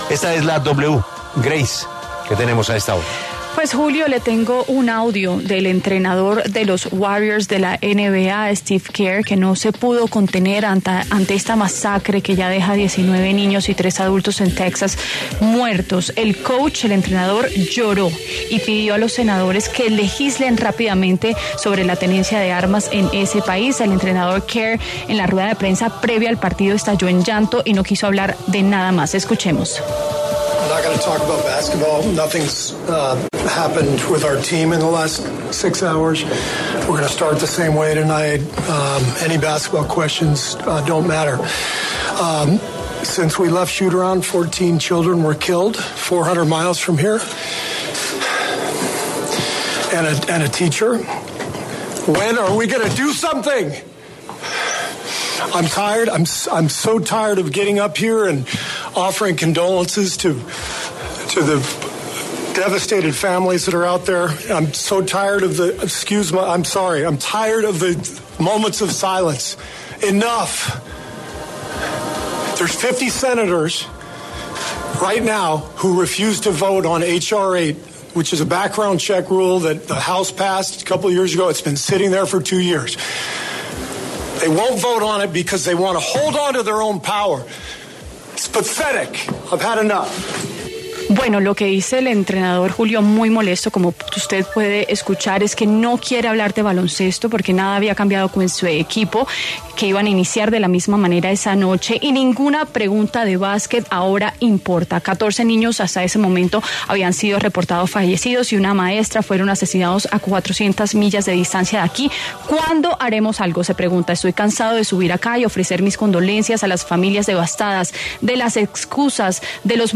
“No voy a hablar de baloncesto hoy (...). Ninguna pregunta de baloncesto importa”, empezó Kerr en su rueda de prensa antes del cuarto partido de la final del Oeste que se juega esta noche en Dallas (Texas) entre los Mavericks y los Warriors (0-3).
“¿¡Cuándo vamos a hacer algo?!”, gritó.
Al borde de las lágrimas y con gesto de rabia en todo momento, Kerr apuntó que está “muy cansado” de presentarse ante los medios para “ofrecer condolencias a las familias devastadas” tras un tiroteo en EE.UU.